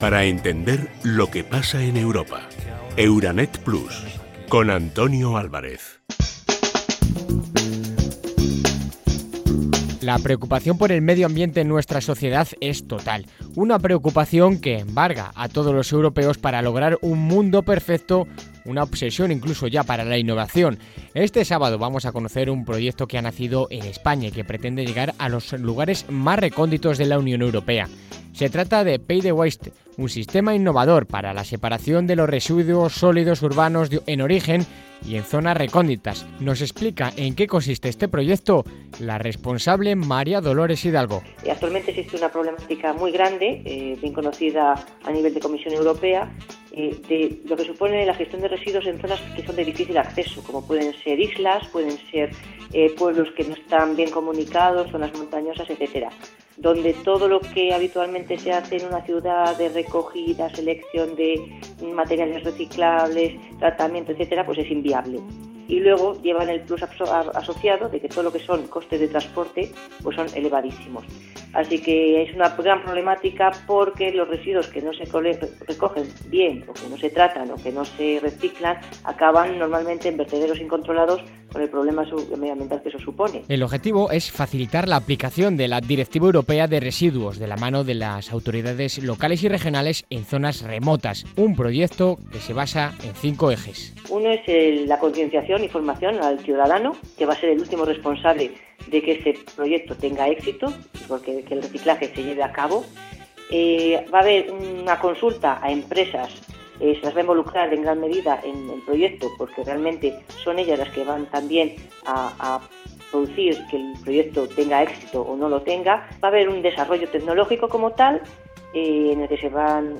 28 November 2015: interview for the EURANET radio about the LIFE PAVEtheWAySTE project. The interview (in Spanish) was broadcasted last November 28th.